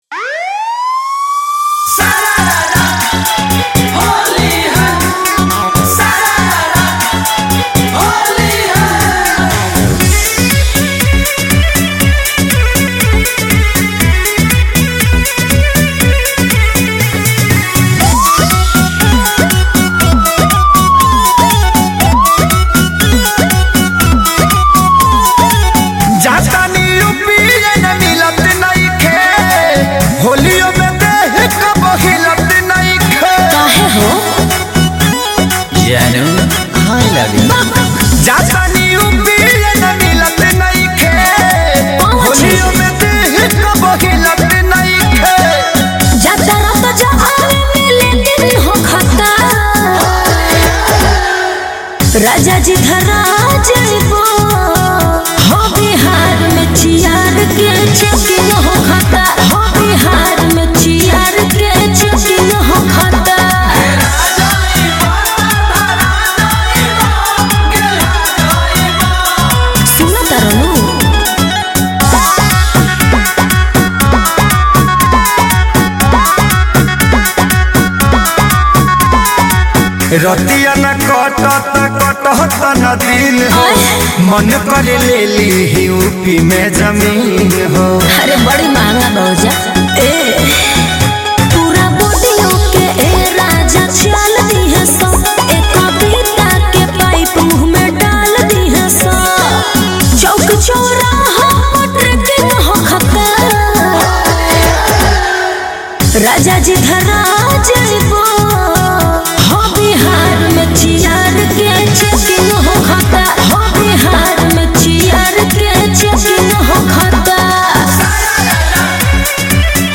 Bhojpuri Holi Song